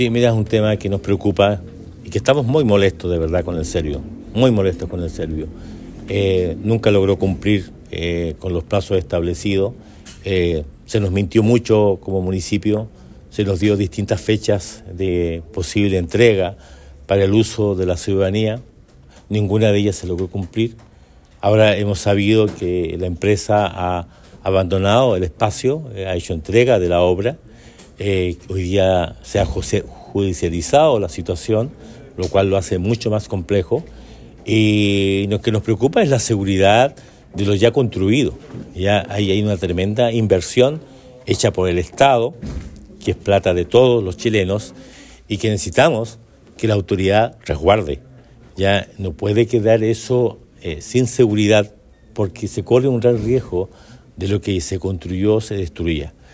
El jefe comunal explicó que la empresa constructora ya finalizó las obras y realizó la entrega técnica del espacio. Sin embargo, al no haberse efectuado la recepción final y sin resguardos de seguridad en el lugar, el proyecto corre el riesgo de sufrir daños o actos de vandalismo, lo cual comprometería la infraestructura construida y podría generar costos adicionales de reparación y mantenimiento en el futuro.